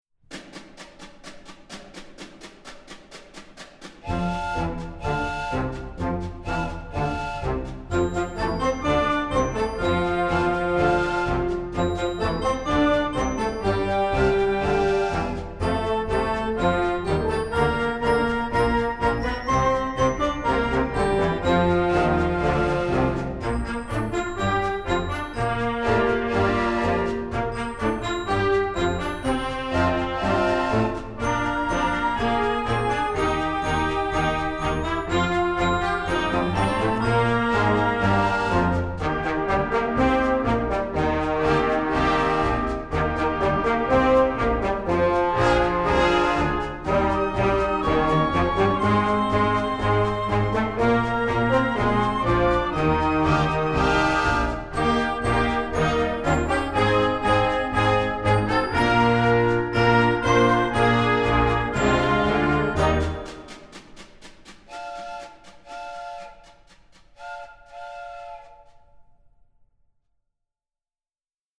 folk, children, jazz, instructional, american, choral